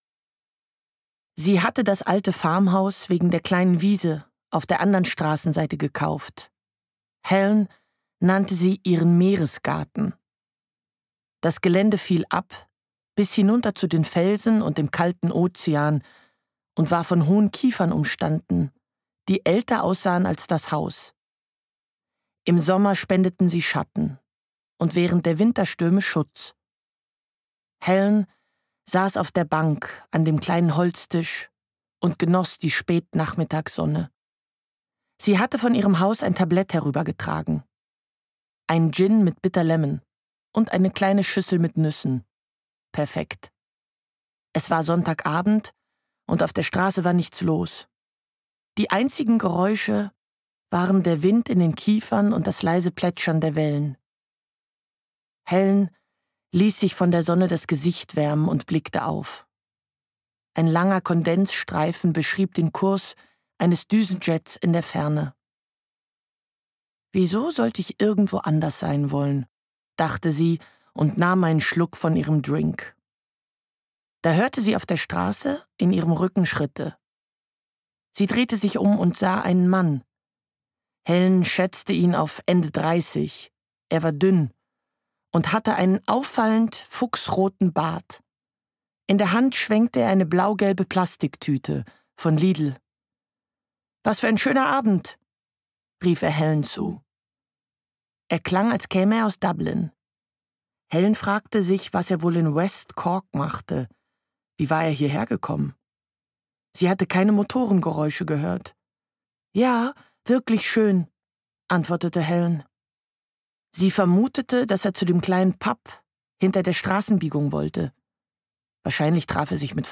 Der Schwimmer Er verschwindet im Meer. Sie glaubt an ein Verbrechen. Graham Norton (Autor) Lina Beckmann (Sprecher) Audio-CD 2023 | 1.